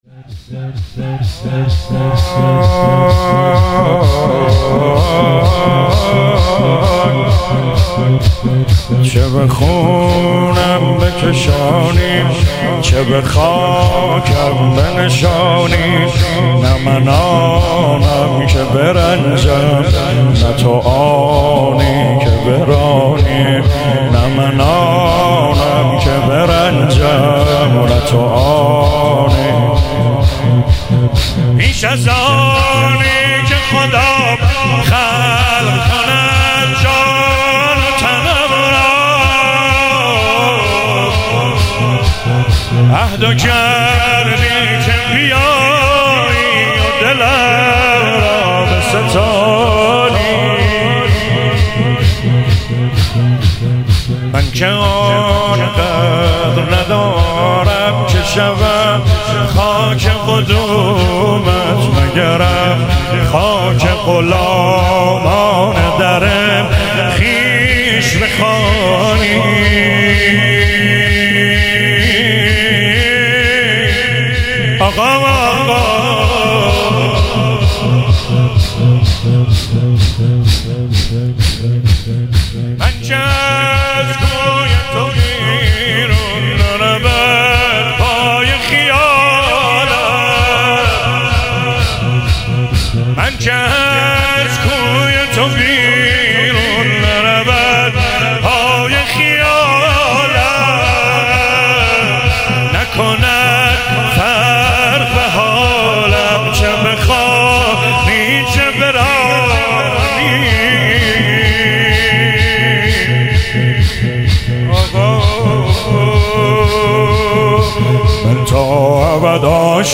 شهادت امام جواد (ع) 98 - شور - چه به خونم بکشانی